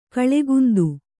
♪ kaḷegundu